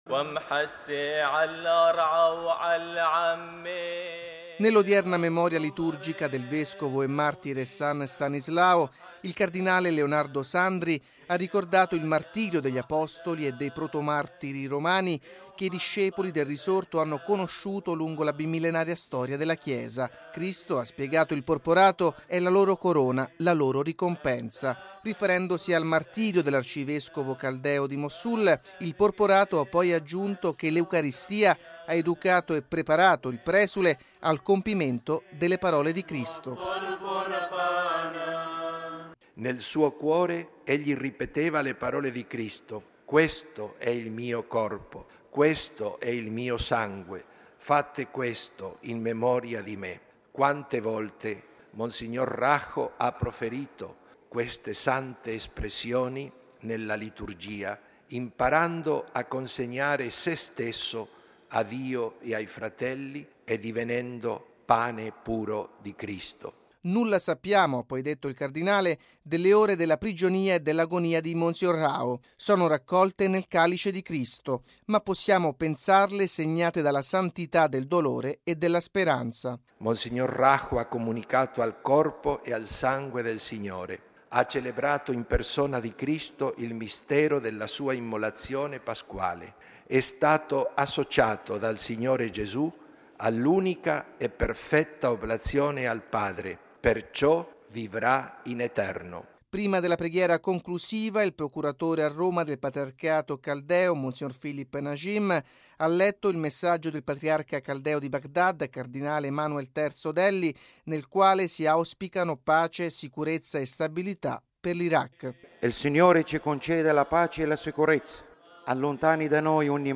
◊   Si è celebrata stamani nella Basilica di San Pietro la Santa Messa nel trigesimo di mons. Paulos Faraj Rahho, arcivescovo caldeo di Mossul, il cui corpo è stato trovato privo di vita lo scorso 13 marzo.
(Canto in arabo)